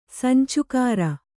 ♪ sancukāra